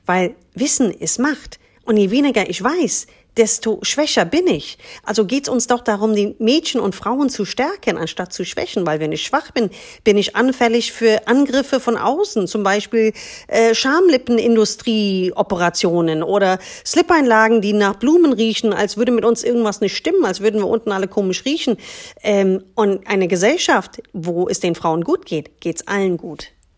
Schluss mit der Scham! - Eine Frauenärztin spricht Klartext